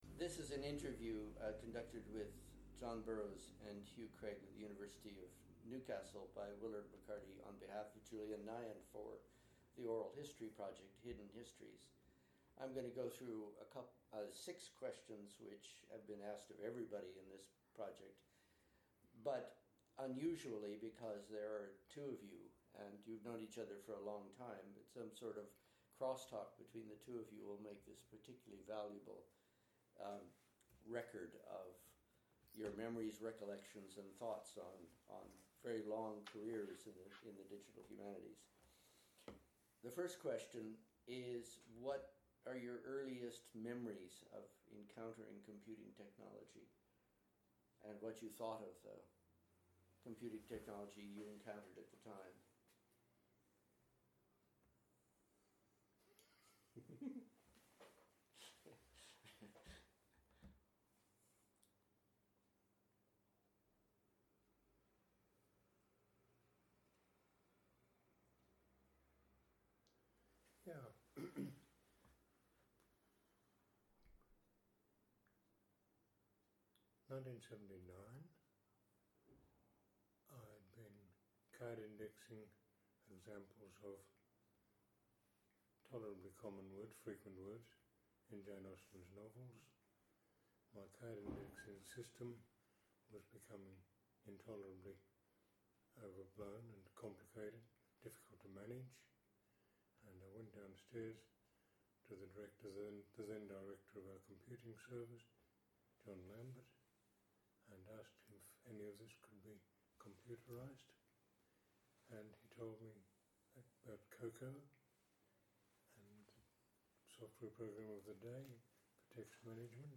Location University of Newcastle, Australia
Interview